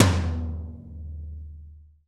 TOM TOM200OL.wav